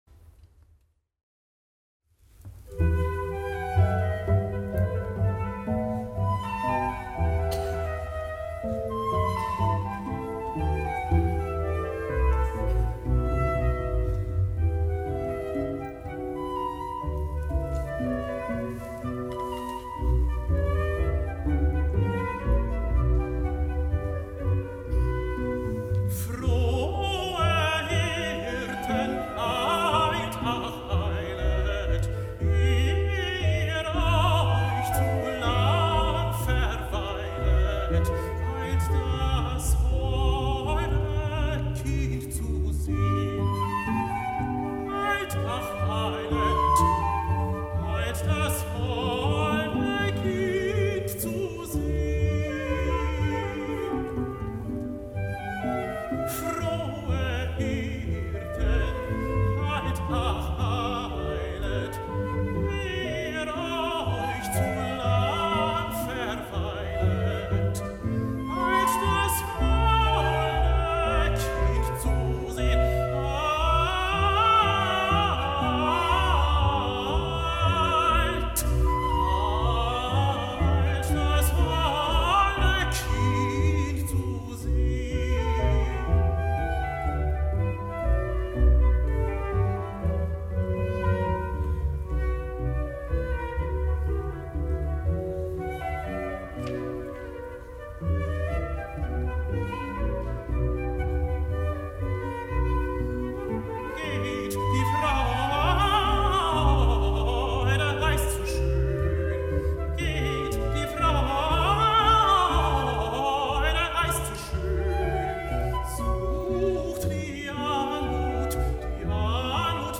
Live Mitschnitte zum Download.